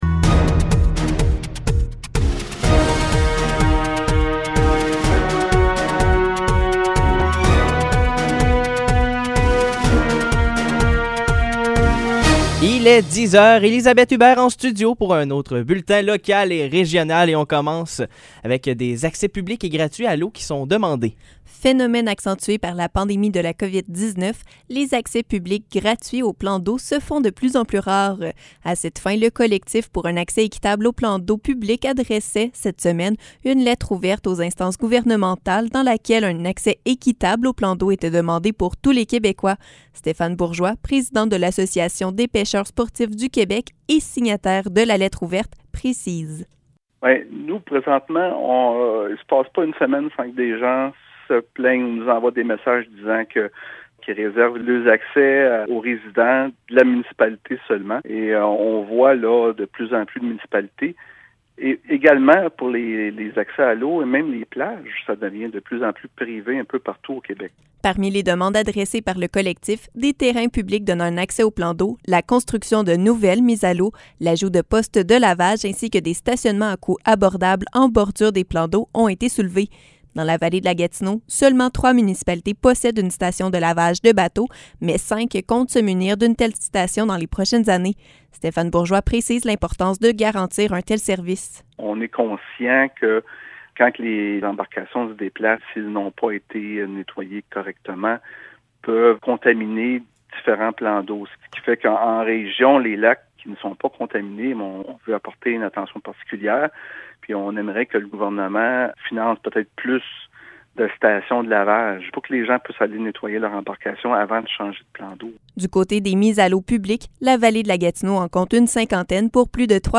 Épisode Nouvelles locales - 30 juillet 2021 - 10 h